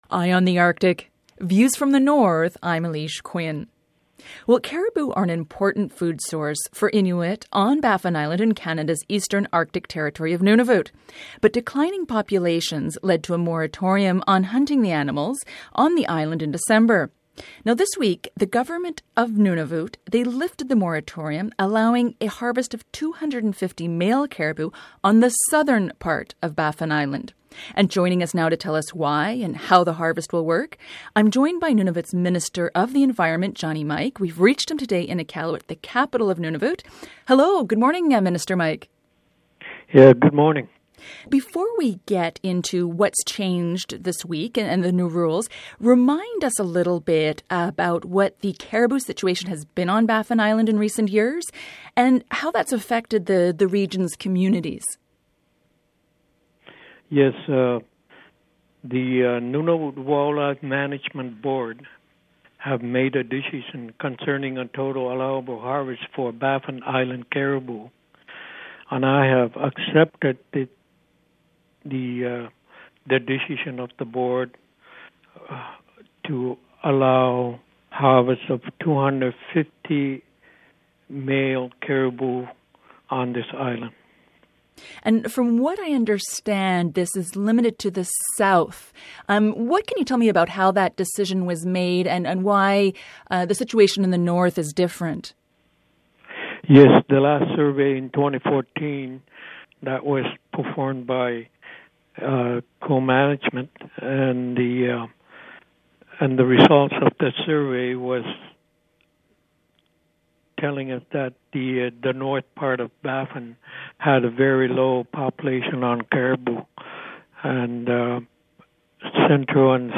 Johnny Mike, Nunavut’s minister of the environment.
Feature Interview